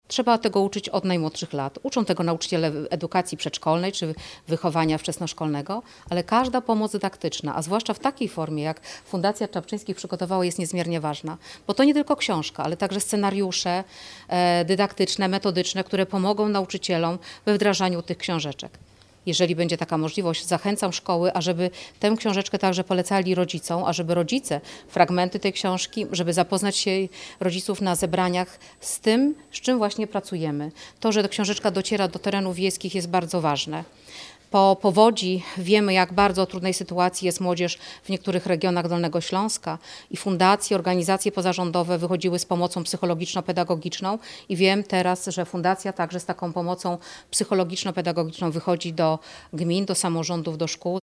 – Książeczka o empatii, o wzajemnym zrozumieniu, o tym, że stereotypy są niekorzystne i szkodliwe – podkreśla Dolnośląska Kurator Oświaty.